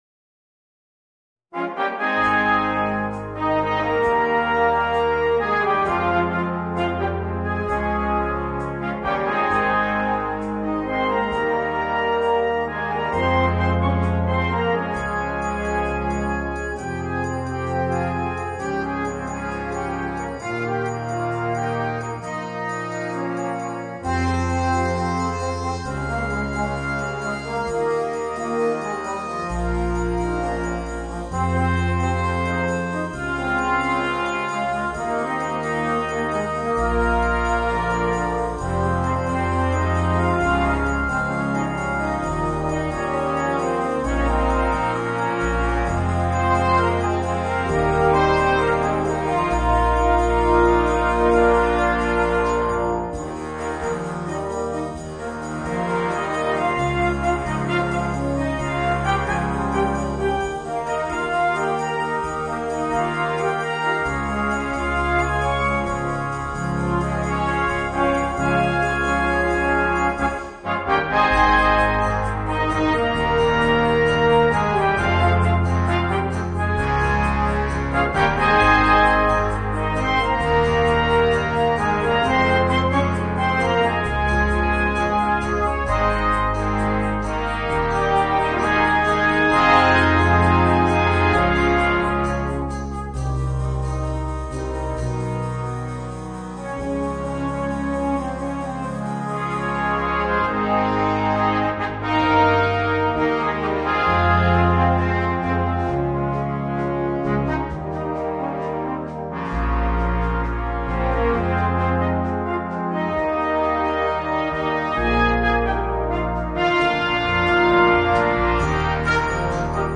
Voicing: Brass Band